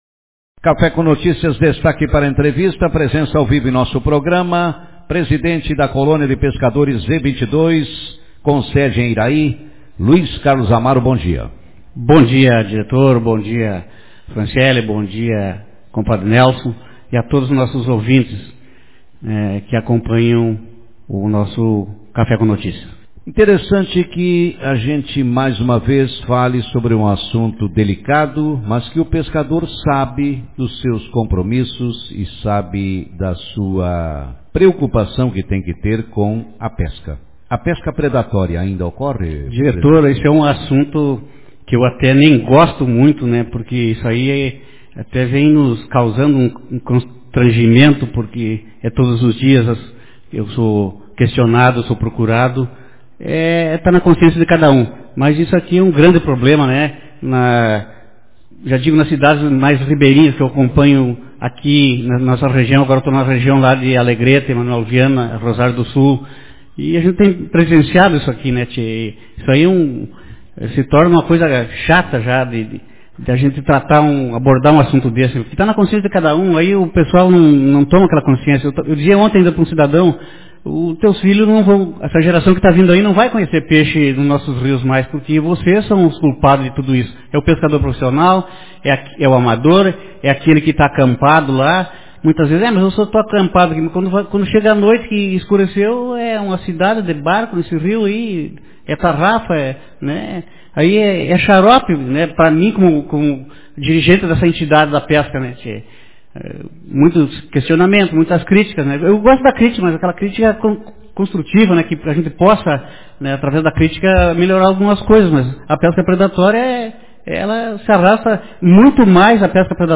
Manchete